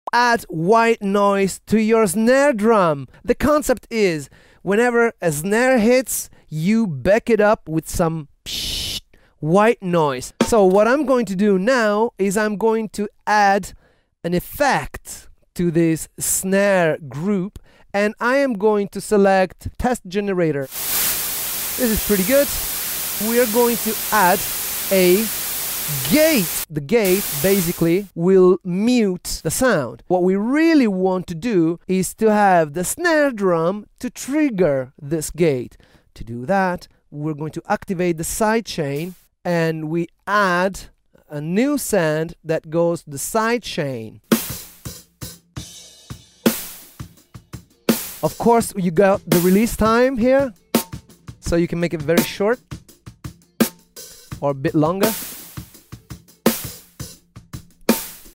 Make your snare sound like from the 80s